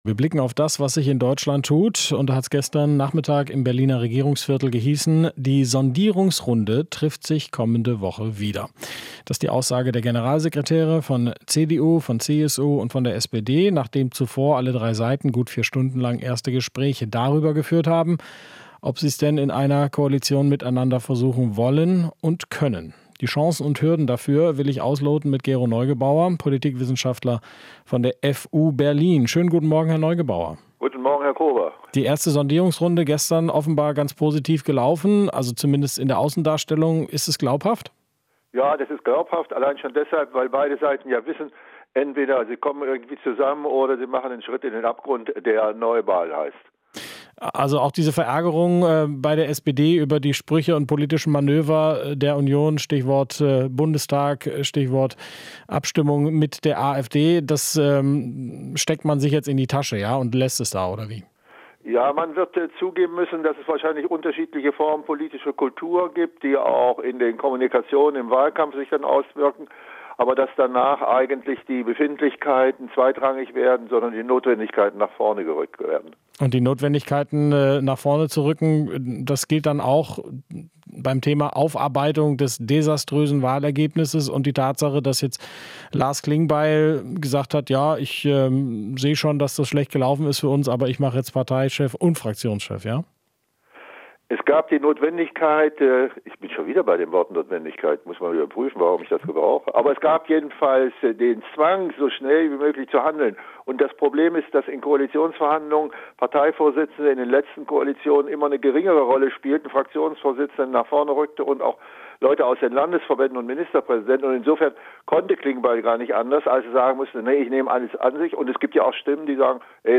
Interview - Experte über Sondierungen: Befindlichkeiten werden zweitrangig